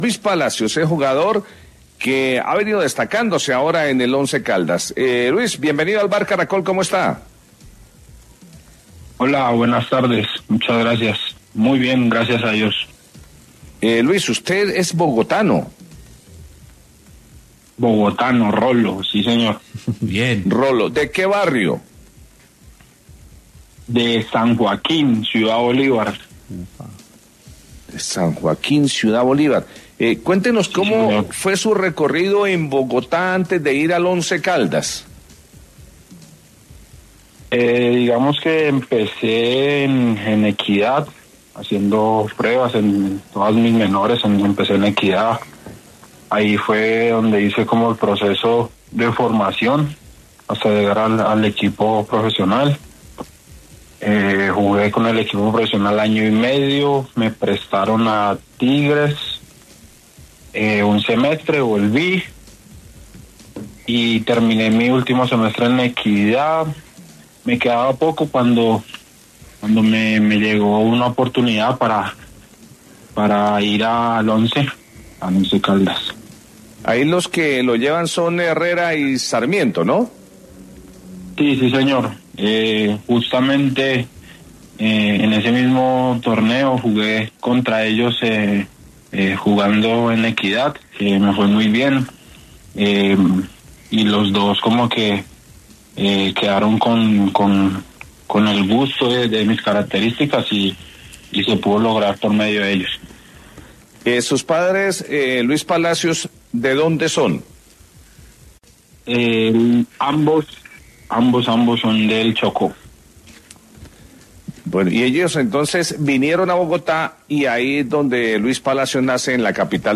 En dialogo con el Vbar de Caracol Radio, el futbolista habló sobre como arrancó su carrera futbolística, además, también mencionó quienes fueron los encargados de llevarlo al Once Caldas. Finalmente se refirió a la felicidad que siente por jugar y compartir con Dayro Moreno.